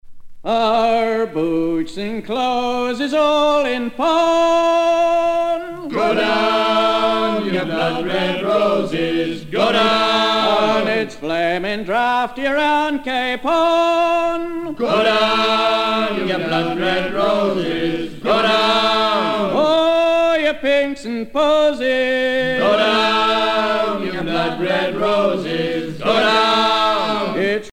maritimes
Pièce musicale éditée